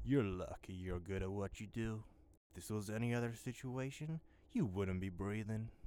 Added all voice lines in folders into the game folder